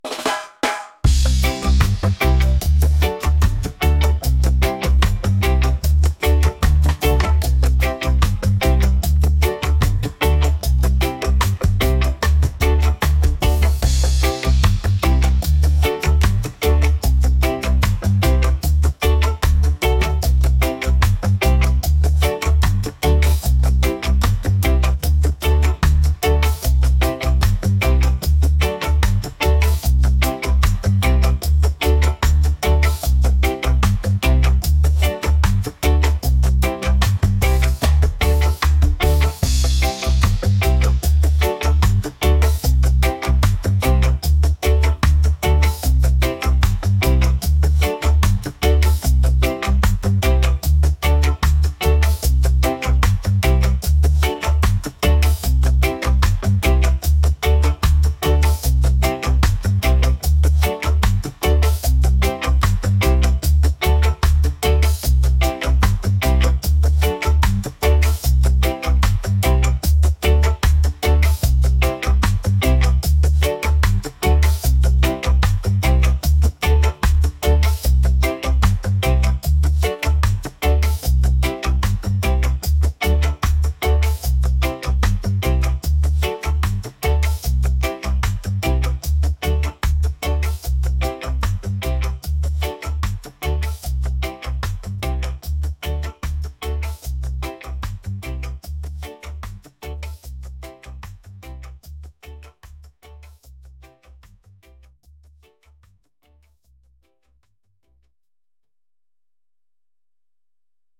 reggae | groovy | upbeat